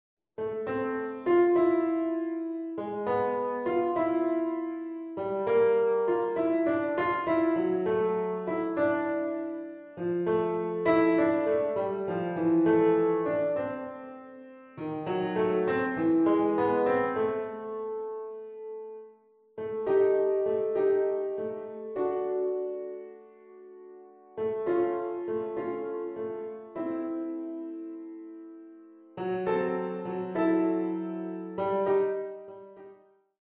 13 Short Piano Pieces for Children
Piano Solo